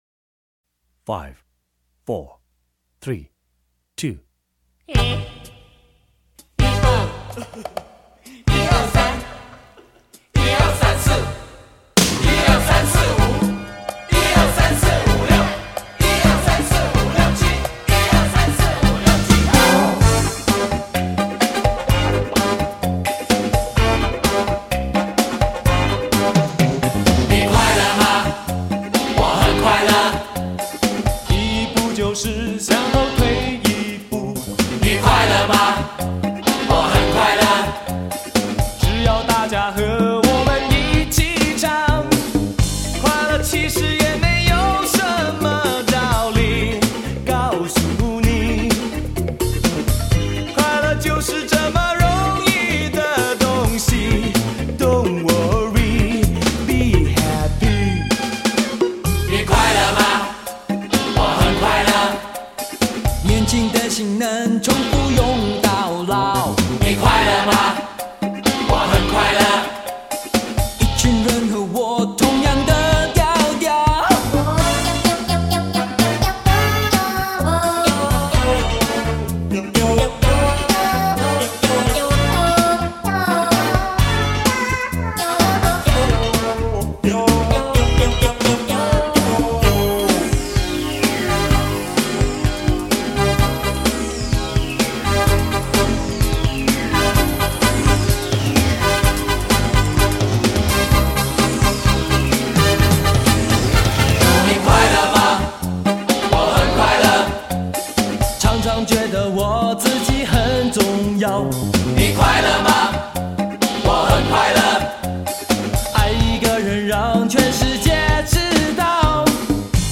色彩缤纷 层次丰富的音乐
整张专辑的音乐虽然不是同一个人做出来的，但同样致力于呈现一种西式的、现代的、年轻的欢愉气氛。
一般乐团专辑，都是一个主唱从头唱到尾，而这张专辑为了突显出“乐团”的整体感觉，五个团员的vocal都收录在内。